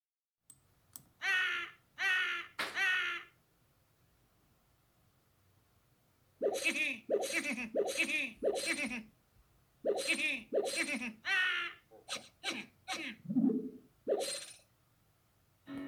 Goblin laughter